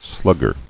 [slug·ger]